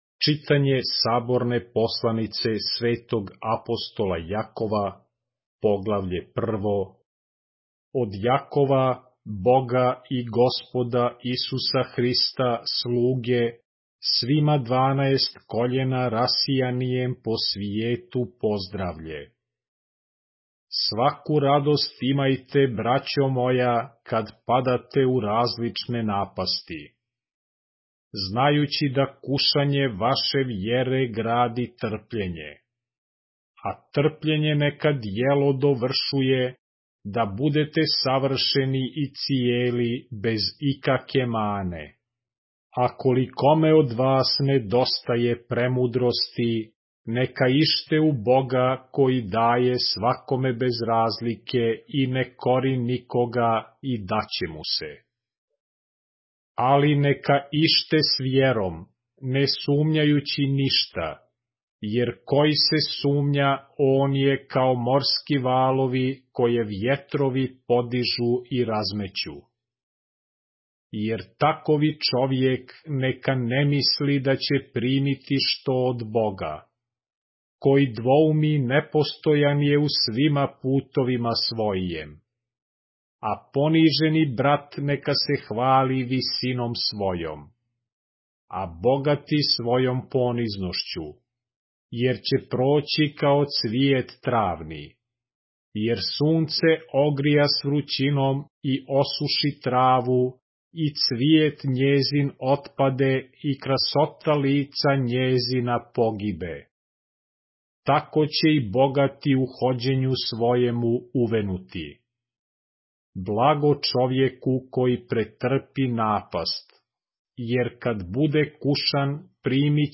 поглавље српске Библије - са аудио нарације - James, chapter 1 of the Holy Bible in the Serbian language